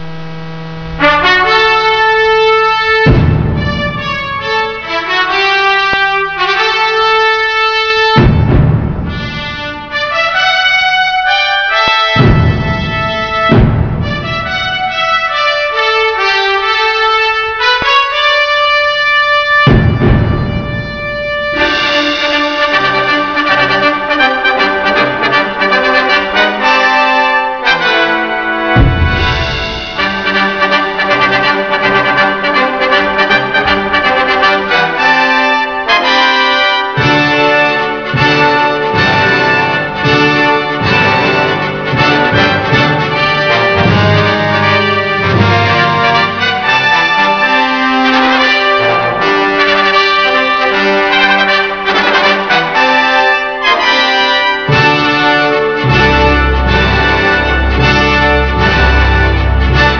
1η Μαθητική Ολυμπιάδα 2000
Ακούστε το εναρκτήριο σάλπισμα για τους αγώνες!